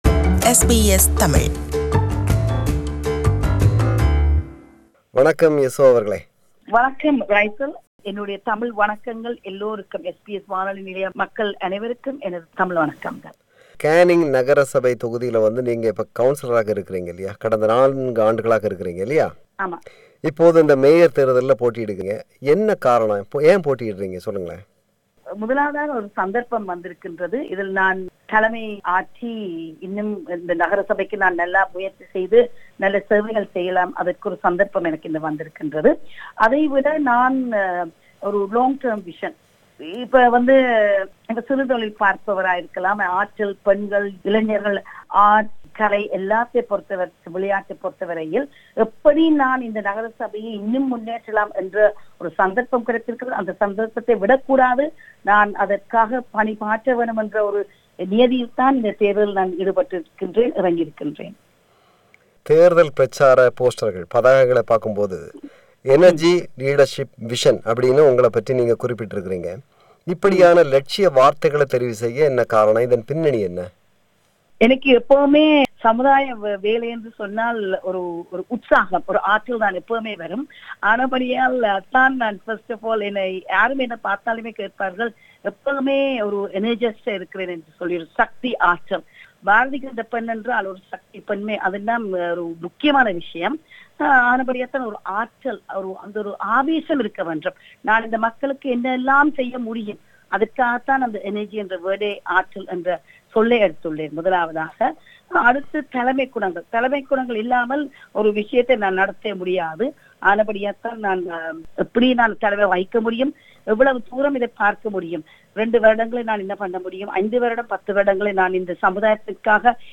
spoke to Councillor Yasotha Ponnuthurai on the challenges she is facing in the mayoral election in the City of Canning.